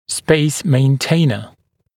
[speɪs meɪn’teɪnə][спэйс мэйн’тэйнэ]устройство для сохранения места